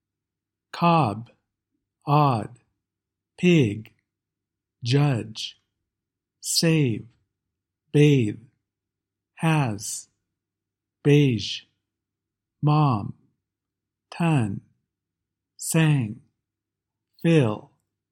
Vowels are long before a voiced consonant (b, d, g, ʤ, v, ð, z, ʒ, m, n, ŋ, l)